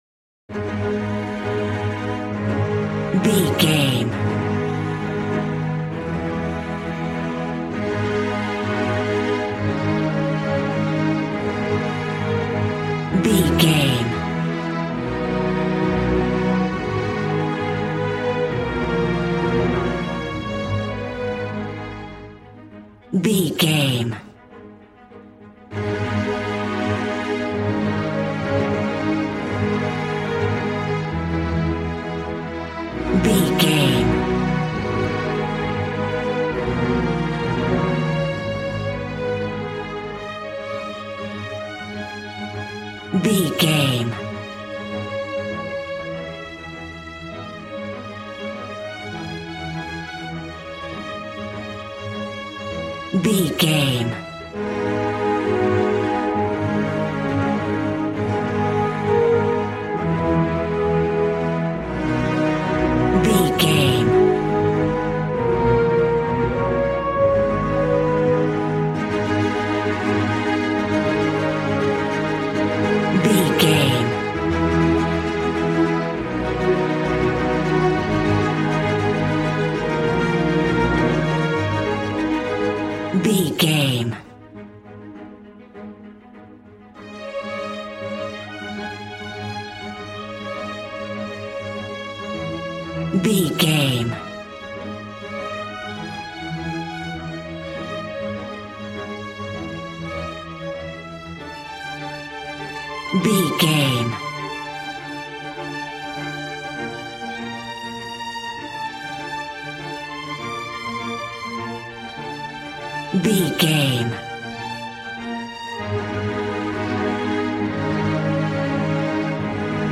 A classical music mood from the orchestra.
Regal and romantic, a classy piece of classical music.
Aeolian/Minor
A♭
regal
cello
violin
strings